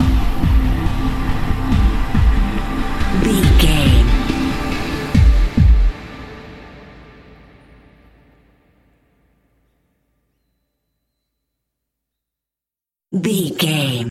Ionian/Major
synthesiser
drum machine
tension
ominous
dark
suspense
haunting
creepy
spooky